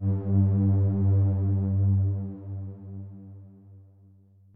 b_basspad_v127l1o3g.ogg